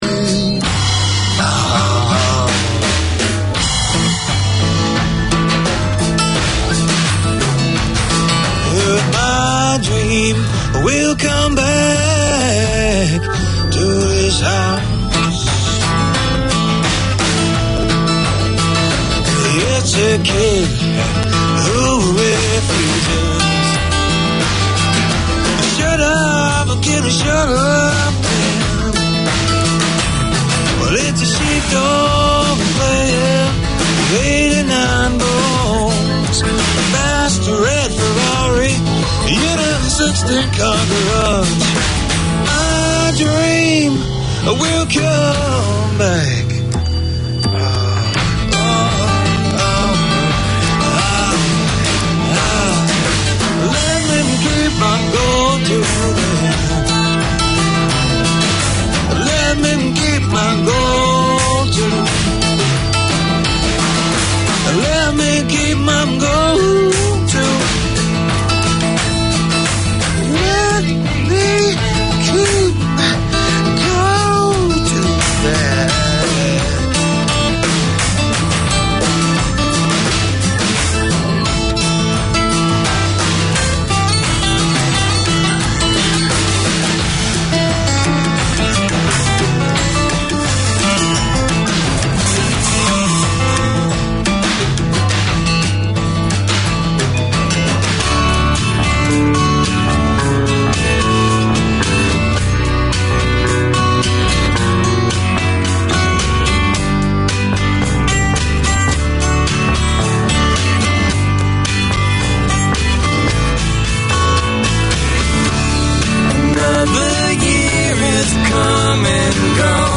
Catering to a wide community of Arabic, Syriac, Chaldean and Kurdish speakers, Voice of Mesopotamia presents an engaging and entertaining hour of radio. Tune in for interviews with both local guests and speakers abroad, a youth-led segment and music from across Mesopotamia.